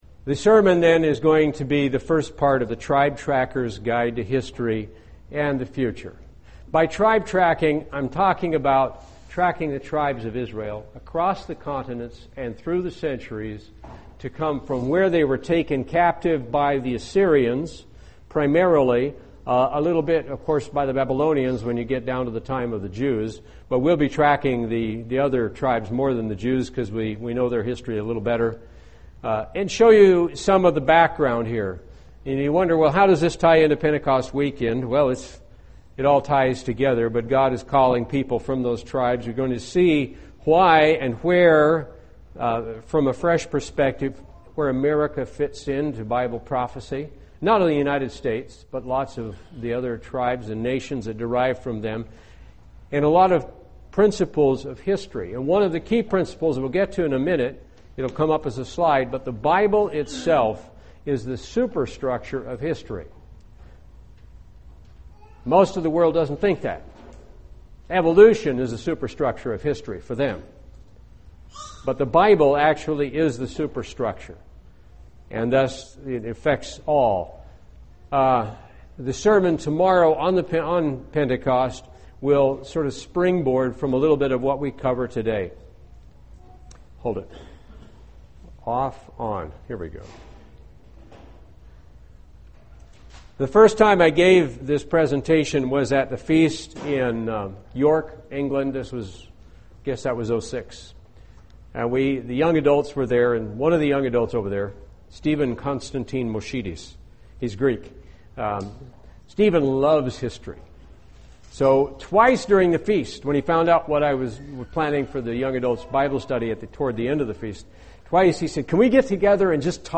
Bible study tracking the descendants of the twelve tribes of Israel.
Given in Charlotte, NC
UCG Sermon Studying the bible?